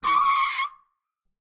skid2.ogg